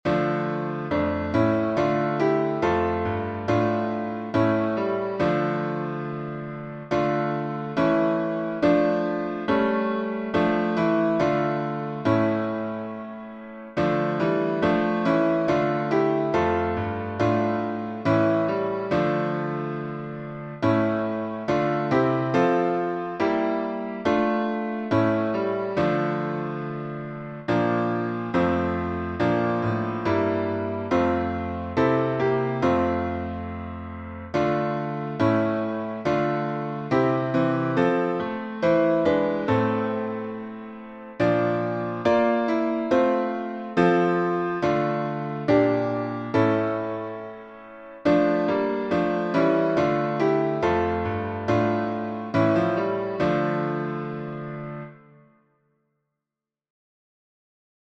Words by Charles Wesley (1707-1788), 1740Tune: ABERYSTWYTH by Joseph Parry (1841-1903)Key signature: D minor (1 flat)Time signature: 4/2Meter: 7.7.7.7.D.Public Domain1.